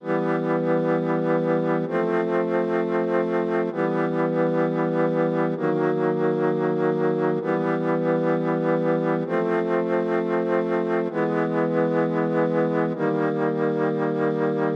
描述：通过听一些拉丁美洲爵士乐开发了一个不错的Rhodes riff，在Madtracker2中对免费的MrRay73 VST进行了测序，并通过键的强度自动模拟实际播放。我还添加了一点点放大器失真与CamelCrush和一些混响以提供空间 如果有人想要原始没有效果只是让我知道。 BPM：171.5 和弦： E G B D F＃E G B C＃F＃ D F A C ED F A B E.
Tag: 短语 VST 即兴重复段 爵士 罗德